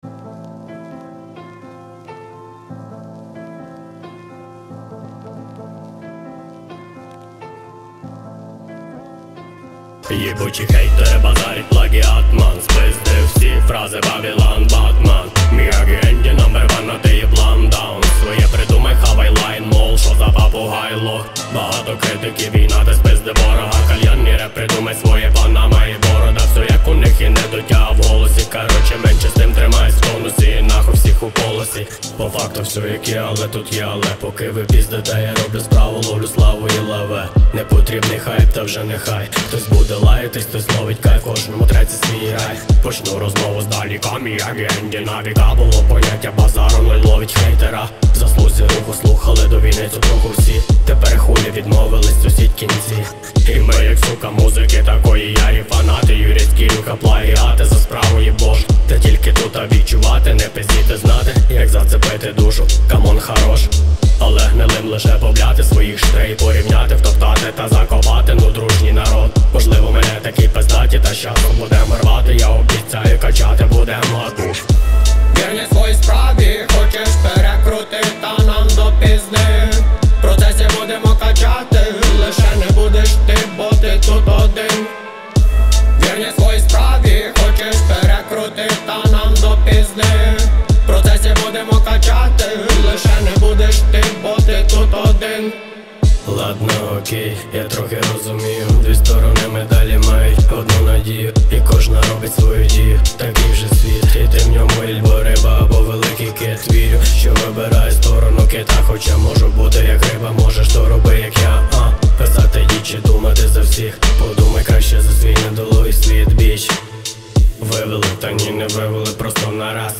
• Жанр: Rap